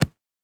punch5.ogg